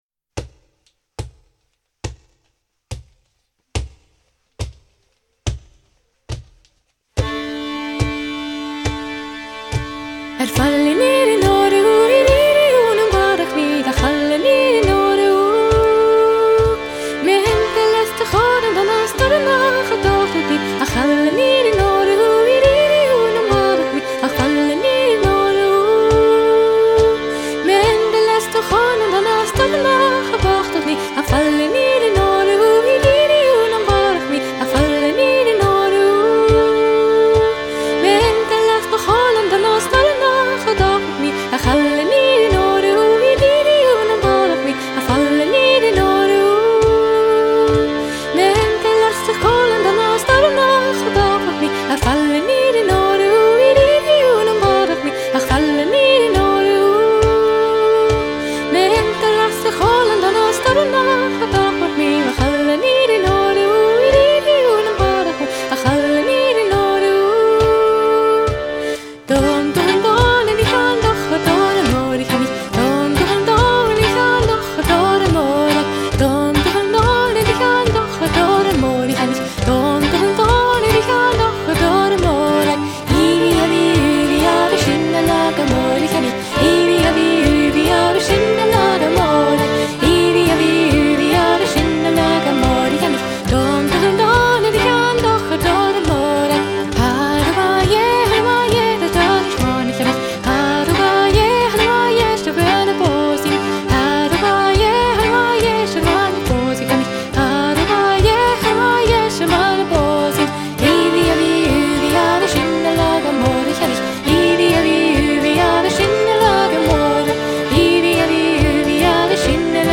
Trio
Mystische Zaubermusik, Irish Pub Stimmung und Folk Tänze für die Hochzeit. Spinning Wheel spielt Celtic Folk aus Irland, Schottland und Wales, Filmmusik aus Herr der Ringe, Game of Thrones und Mittelaltermusik.
spinning-wheel-puirt-a-beul.mp3